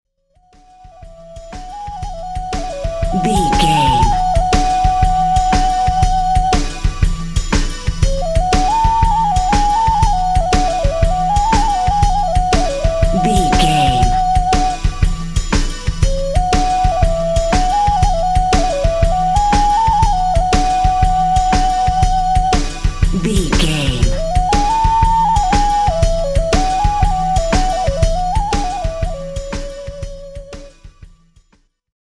Ionian/Major
folk music